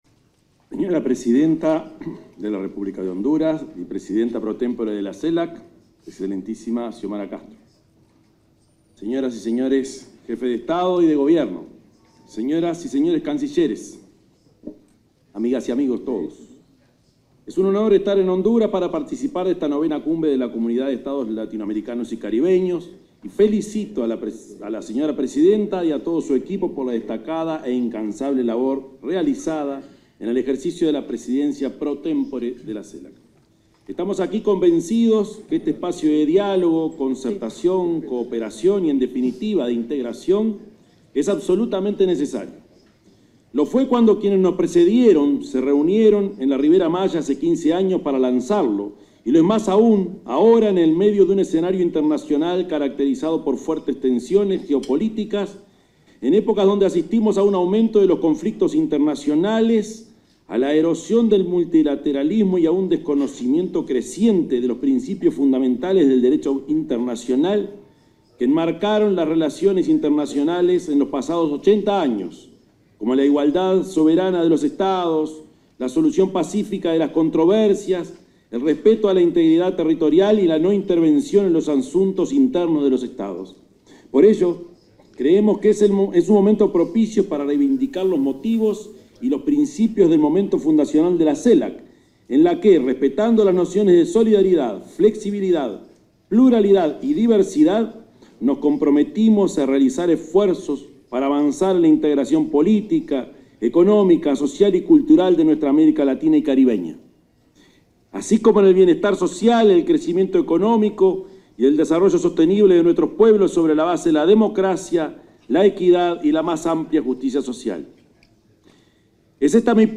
Palabras del presidente Yamandú Orsi en IX Cumbre de Celac
El presidente de la República, Yamandú Orsi, participó de la IX Cumbre de la Comunidad de Estados Latinoamericanos y Caribeños (Celac).